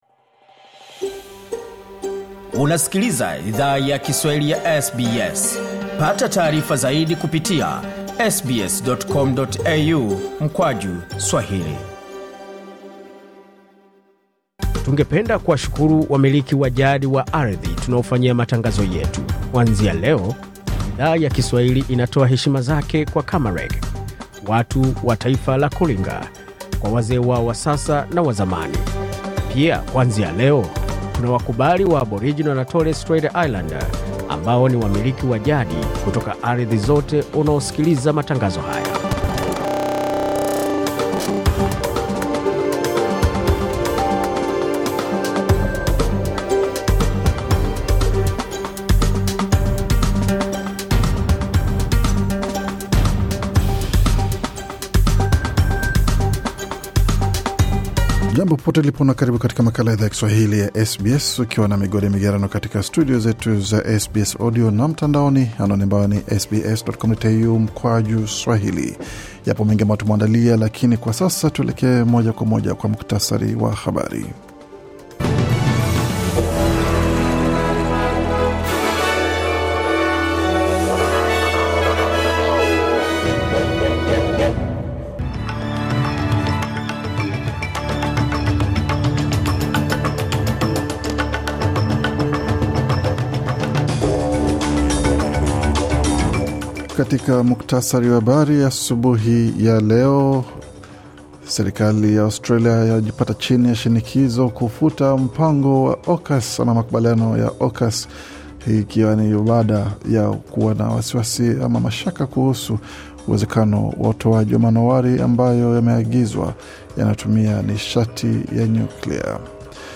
Taarifa ya Habari 18 Machi 2025